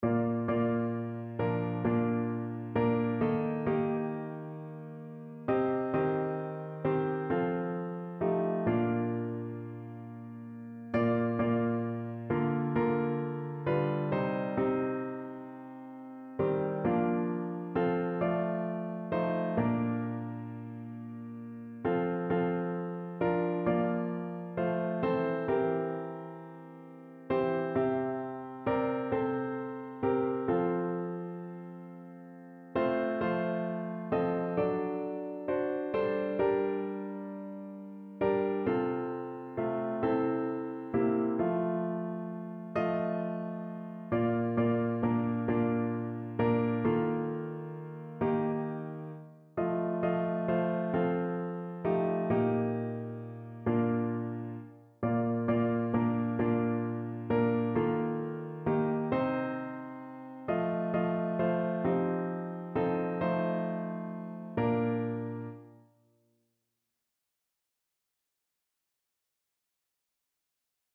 Errettung des Sünders Evangeliumslieder
Notensatz (4 Stimmen gemischt)